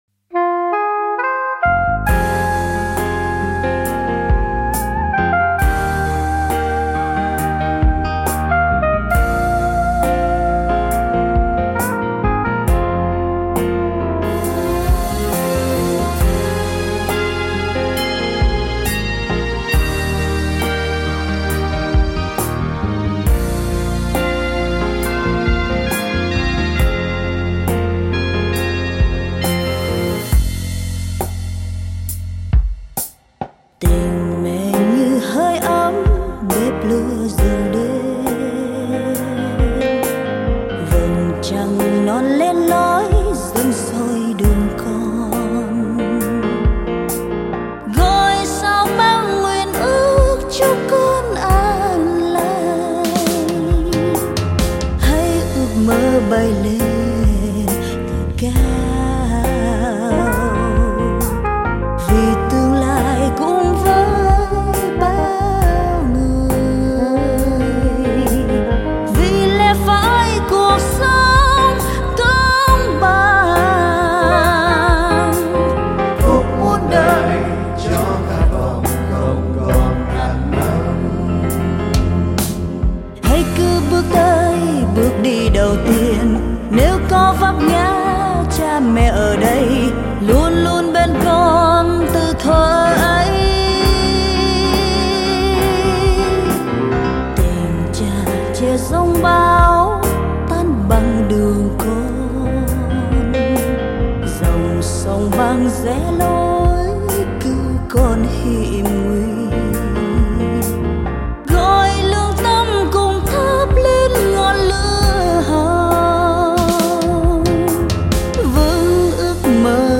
Sáng tác: Trần Huỳnh Duy Thức
Anh chị em thân hữu cùng chung tay thực hiện bài hát này như một lời cầu chúc bình an cho anh Trần Huỳnh Duy Thức.
tinh_me_nang_buoc_con_di-tran_huynh_duy_thuc-giong_nu.mp3